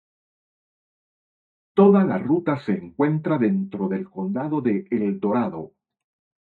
Pronounced as (IPA)
/ˈruta/